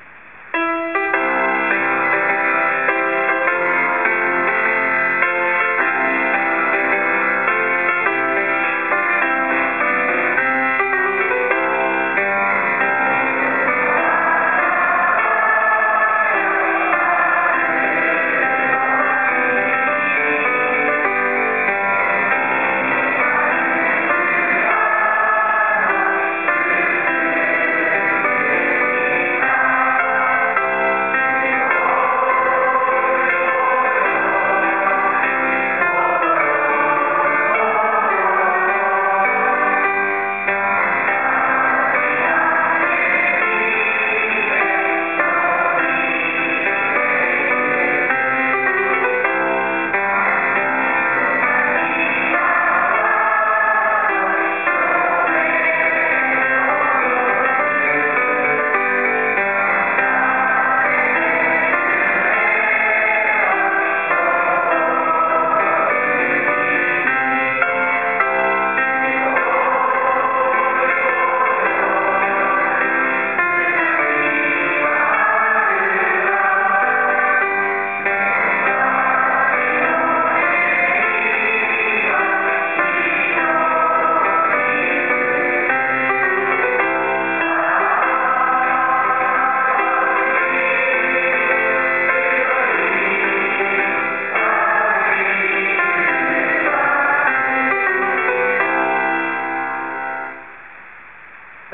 （※ 全校生徒による歌声 平成26年３月13日 録音)